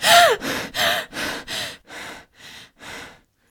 panic_w_0.ogg